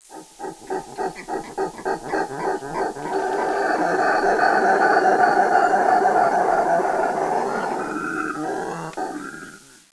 They are the noisiest animal in creation and they can be heard up to a mile away.
Howler Monkey
howlermonkey_sound.wav